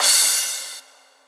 cymbal02.ogg